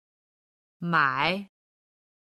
「买(mǎi)」の中国語発音
三声の特徴でもある、ある程度高い位置から音を出して、一旦音を下げ、また盛り上げるように音を出す。
「买(mǎi)」女性音源
mai3-nuxing.mp3